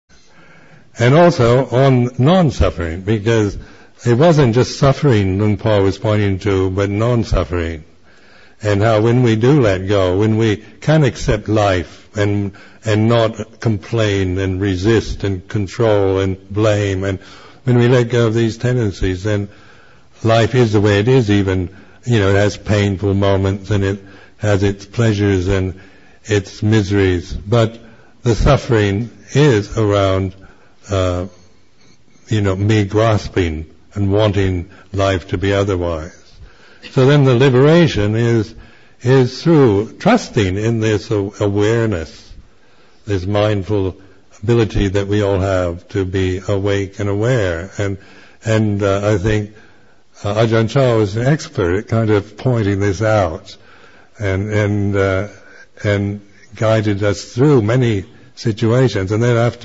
Reflection by Ajahn Sumedho.
Remembering Ajahn Chah Weekend [2001], Session 16, Excerpt 6